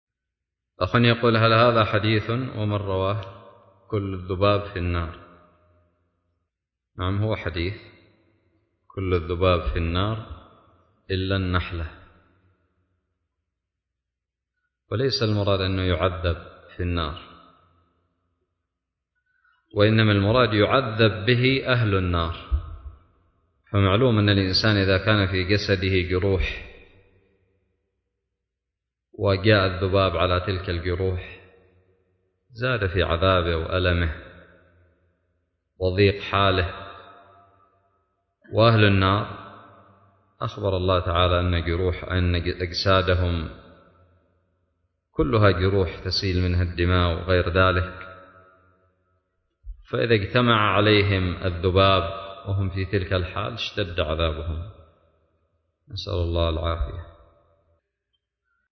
:العنوان فتاوى حديثية :التصنيف :تاريخ النشر 98 :عدد الزيارات البحث المؤلفات المقالات الفوائد الصوتيات الفتاوى الدروس الرئيسية هل هذا حديث كل الذباب في النار ؟